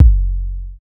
Waka KICK Edited (33).wav